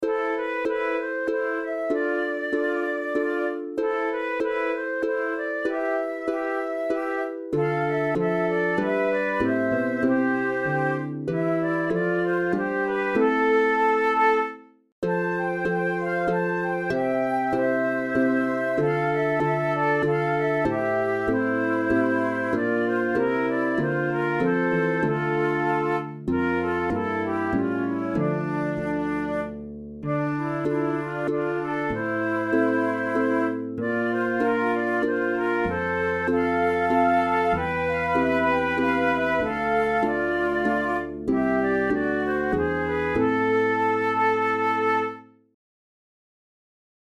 InstrumentationTwo Flutes and Piano
KeyA minor
Time signature3/4
Tempo96 BPM
Contemporary